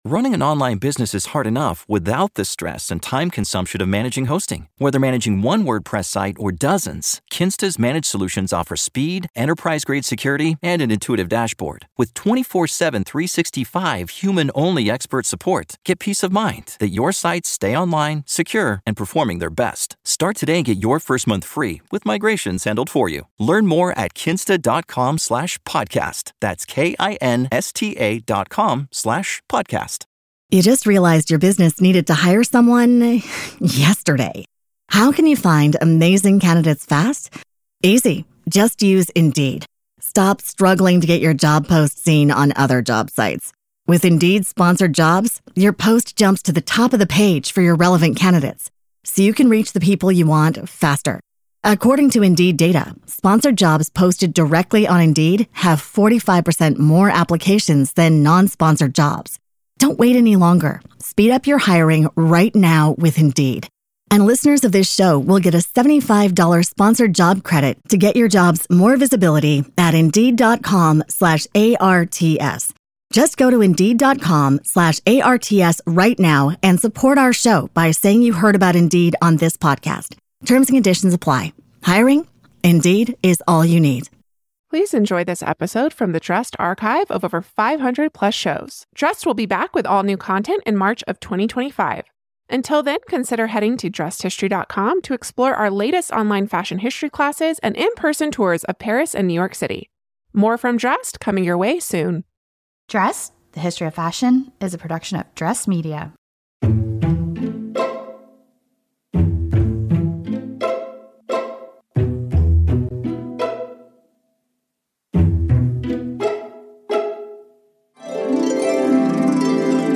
1 Invisible Beauty: An Interview with Bethann Hardison (Dressed Classic) 47:40